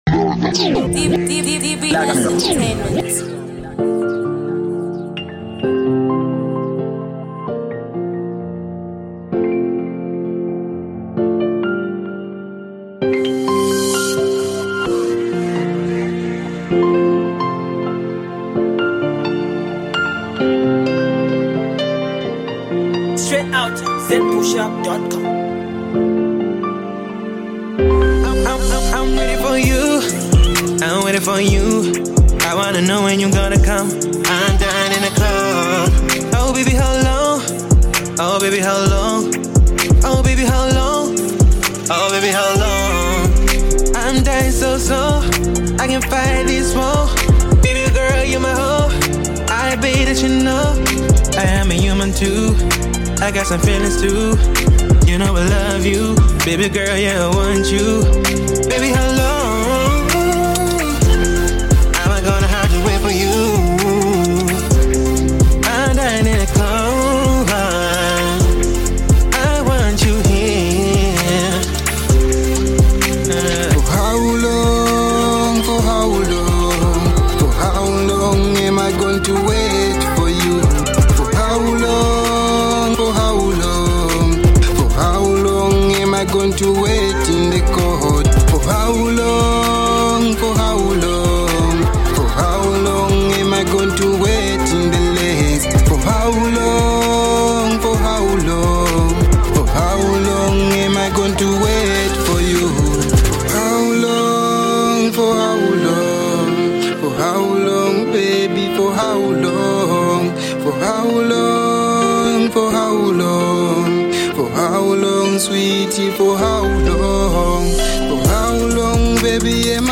RnB genre